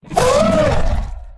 combat / creatures / ryuchi / she / hurt3.ogg
hurt3.ogg